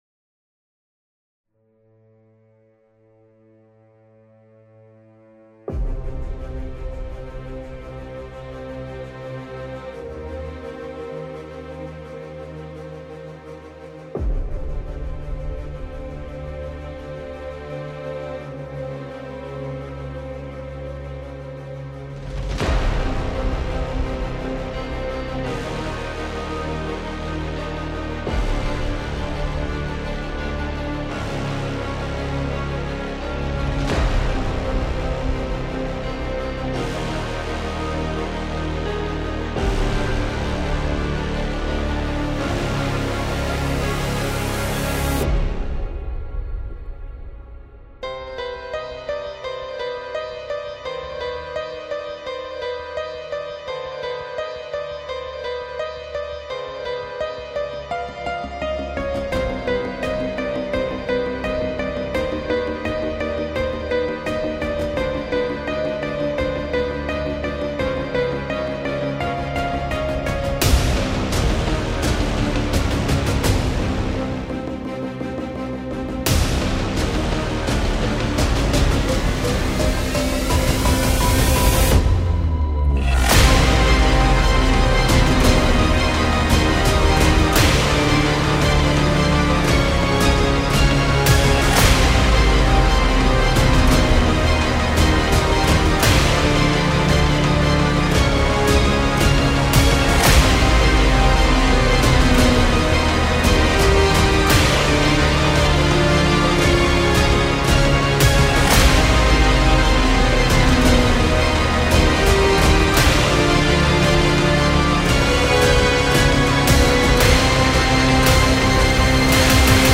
「インスト部門」最優秀作品です。